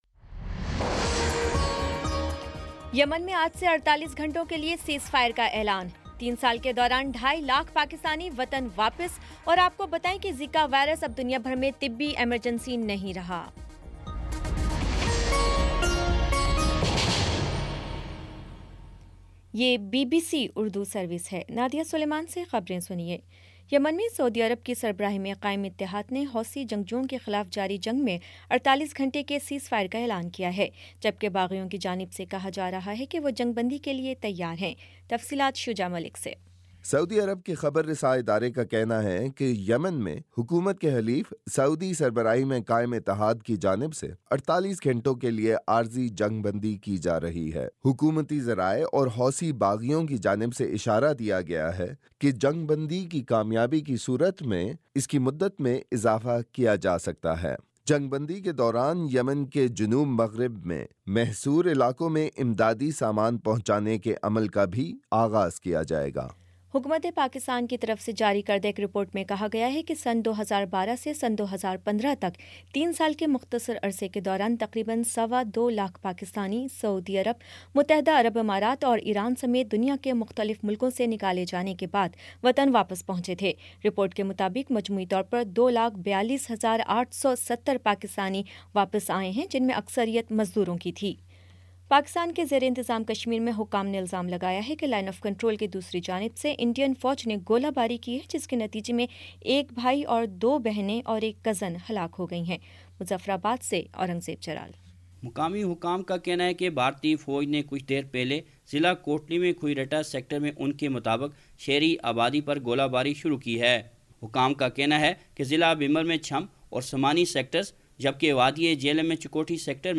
نومبر 19 : شام سات بجے کا نیوز بُلیٹن